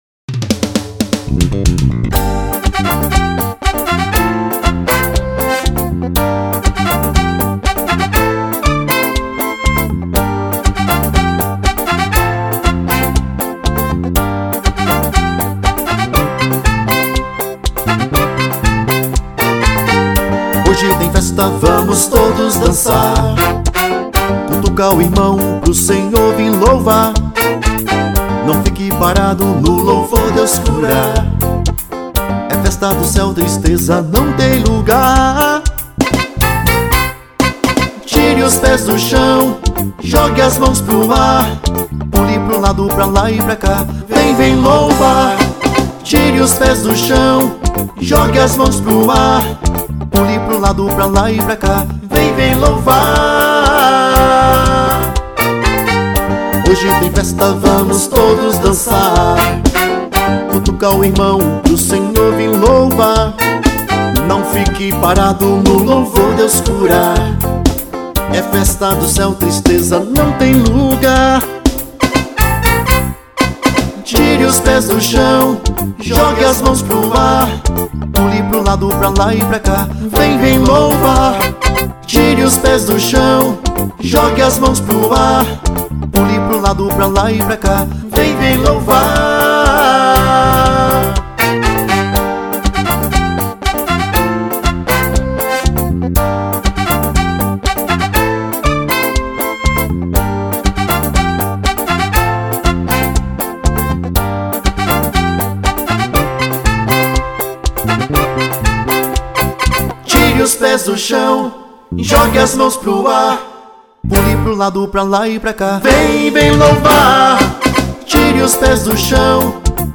Gênero Católica.